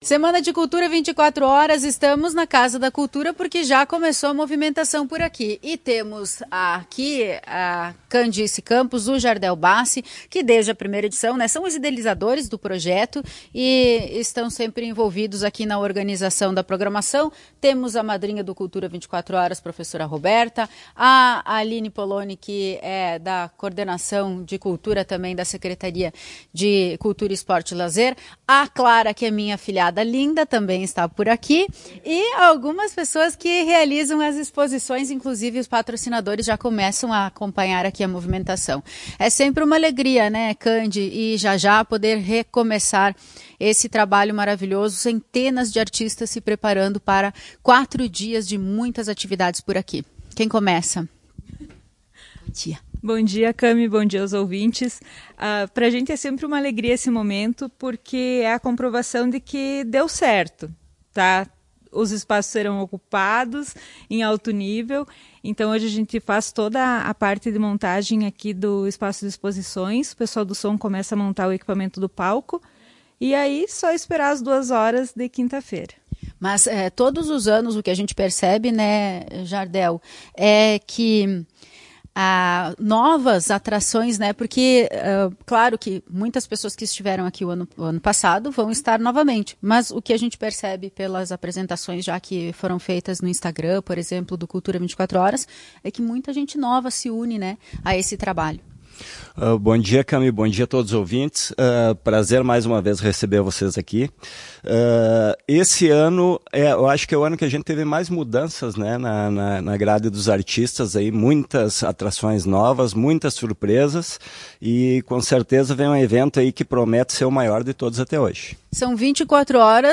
Na manhã desta terça-feira, 16/05, em entrevista à Tua Rádio Alvorada, os idealizadores do encontro dos mais diversos setores da arte regional comemoraram.